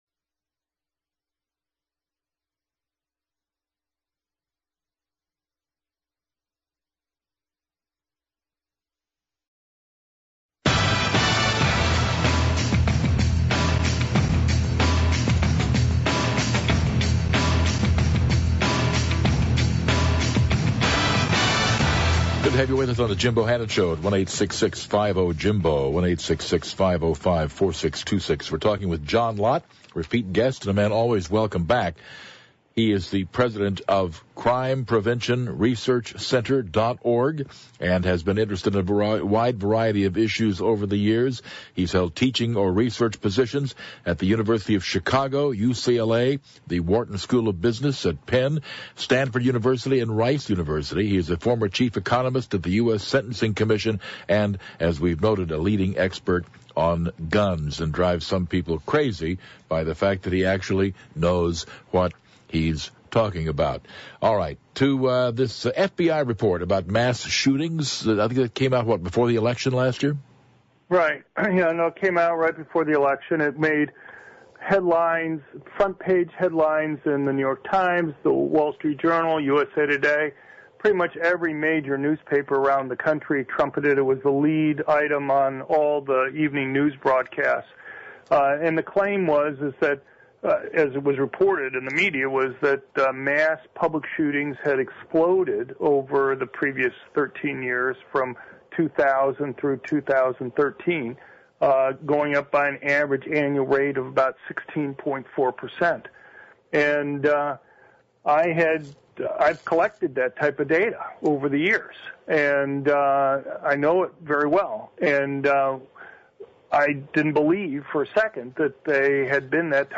John Lott talked to Jim Bohannon on Thursday, June 4th during the 11 PM hour. Jim’s radio show has long been the fifth most popular talk radio show in the country.